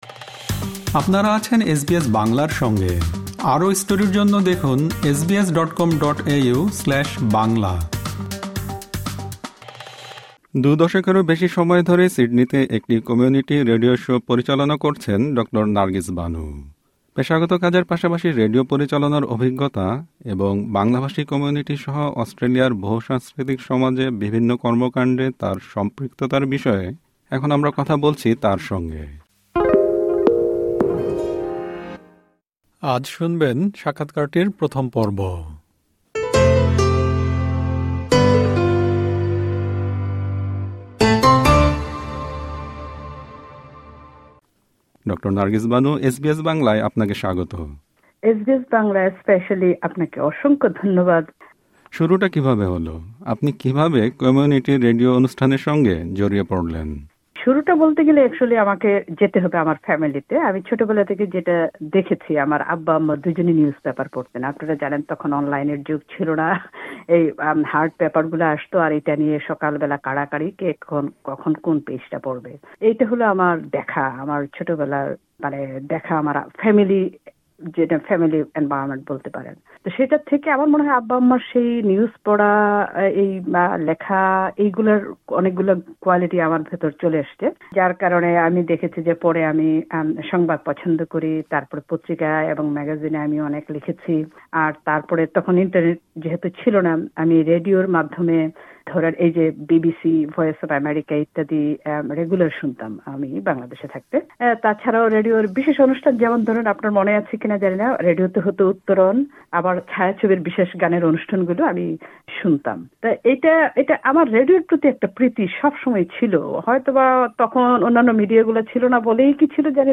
সাক্ষাৎকারের প্রথম পর্বটি